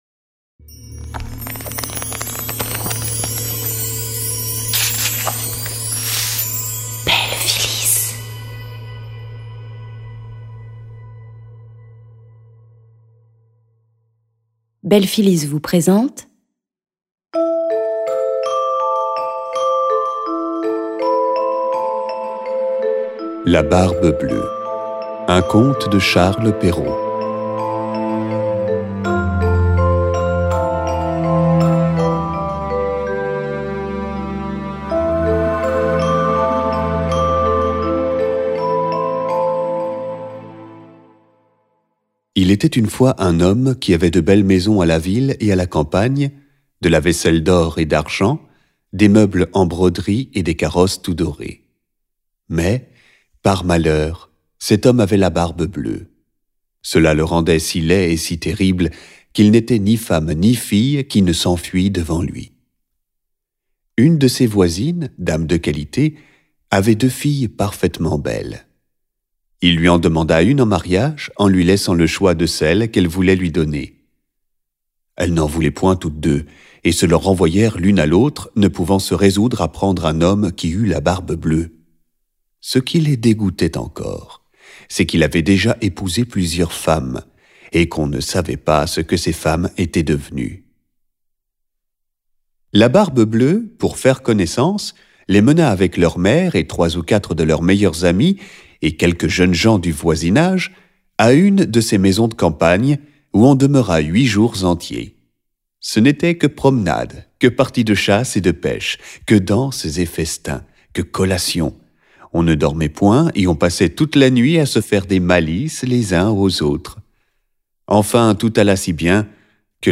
je découvre un extrait - La Barbe bleue de Charles Perrault